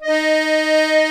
D#4 ACCORD-R.wav